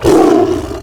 CosmicRageSounds / ogg / general / combat / creatures / tiger / she / hurt3.ogg
hurt3.ogg